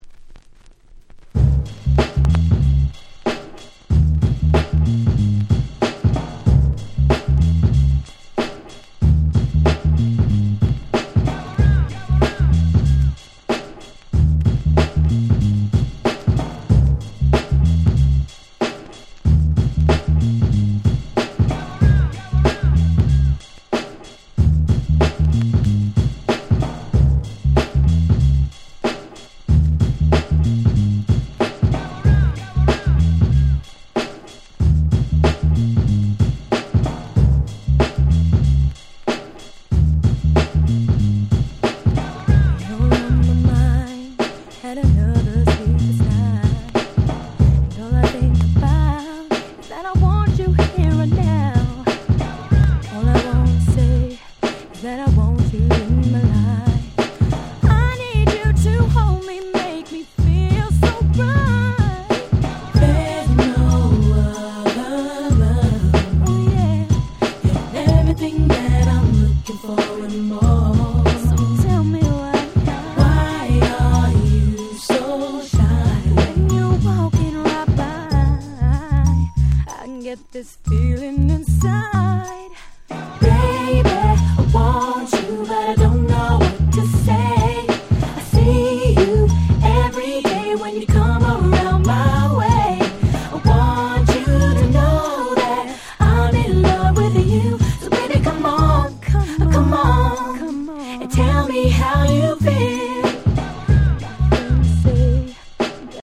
00' Very Nice R&B !!
キャッチー系